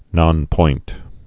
(nŏnpoint)